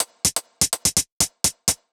Index of /musicradar/ultimate-hihat-samples/125bpm
UHH_ElectroHatD_125-04.wav